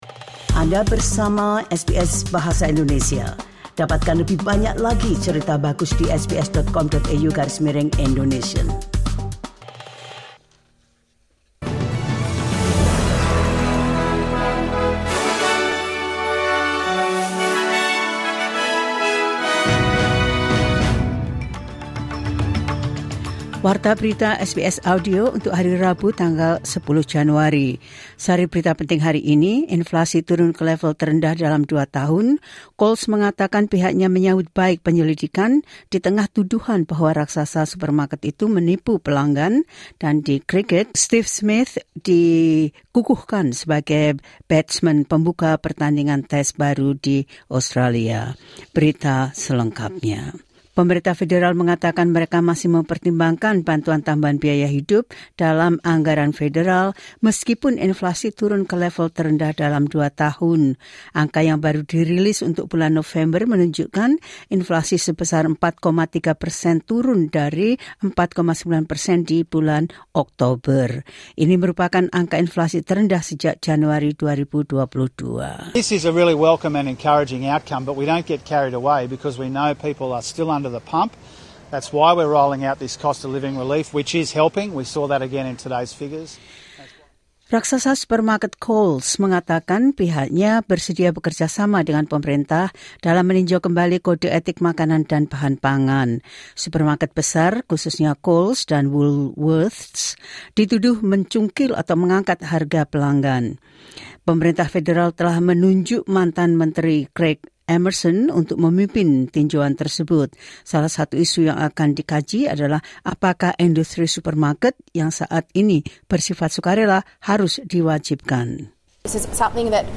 Berita terkini SBS Audio Program Bahasa Indonesia – 10 Jan 2024
The latest news of SBS Audio Indonesian program – 10 Jan 2024.